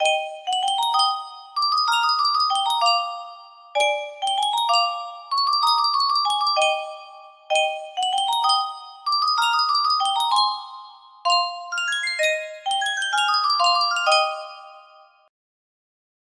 Yunsheng Music Box - Over the Waves 6823 music box melody
Yunsheng Music Box - Over the Waves 6823
Full range 60